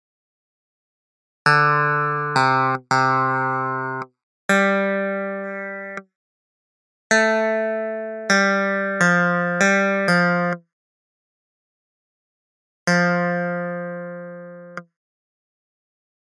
Key written in: F Major
Each recording below is single part only.
a reed organ